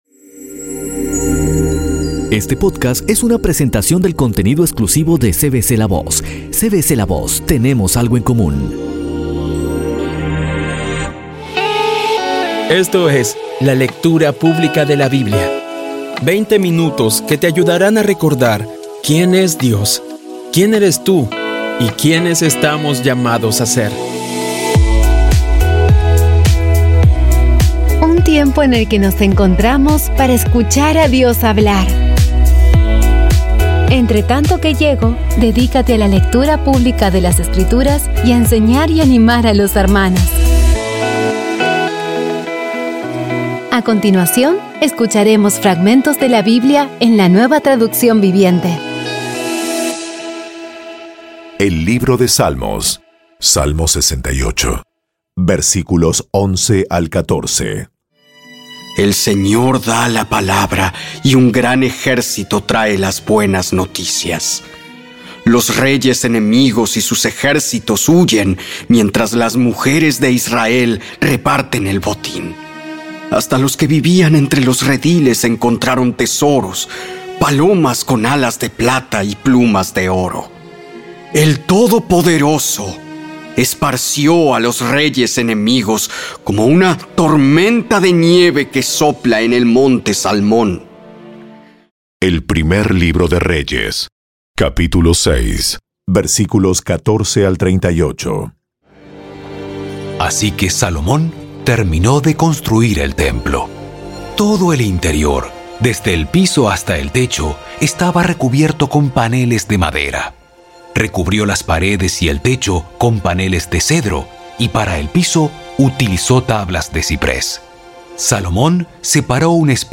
Audio Biblia Dramatizada Episodio 151
Poco a poco y con las maravillosas voces actuadas de los protagonistas vas degustando las palabras de esa guía que Dios nos dio.